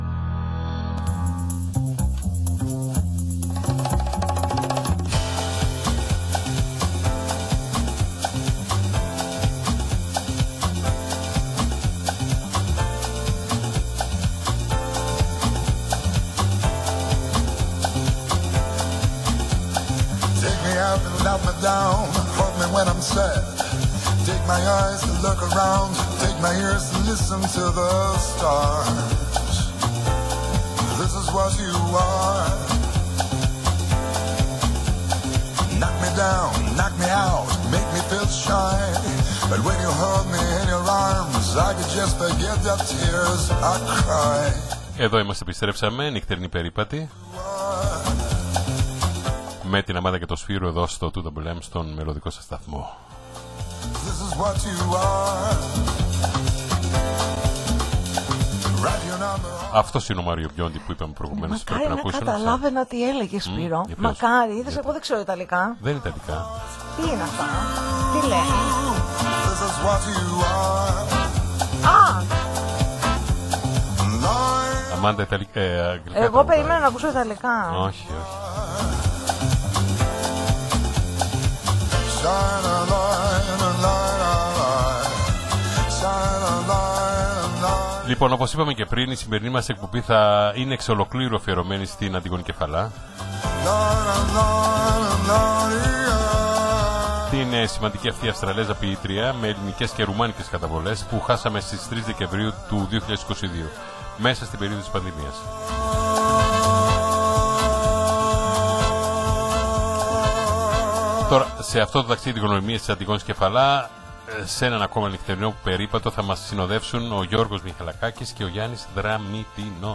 ραδιοφωνική εκπομπή